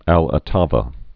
(ălə-tävə, älō-)